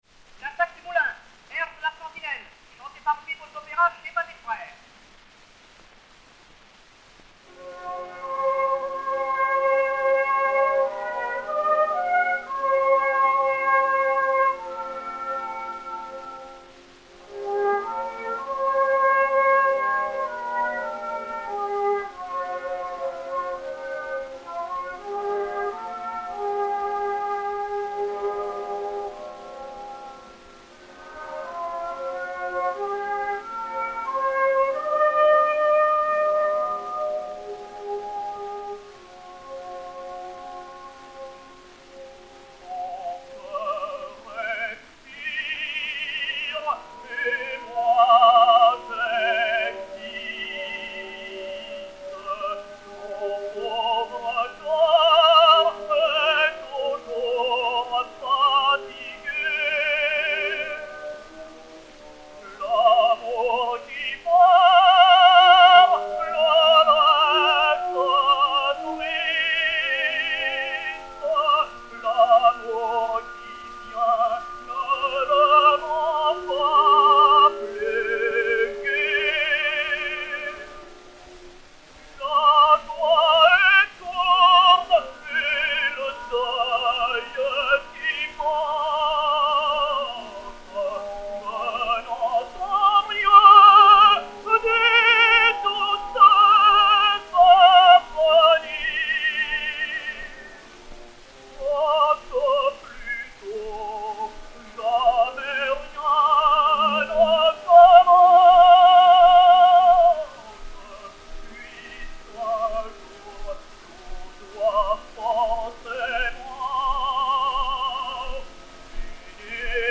et Orchestre
Pathé saphir 90 tours 4663, enr. à Paris en 1906/1907